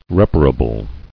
[rep·a·ra·ble]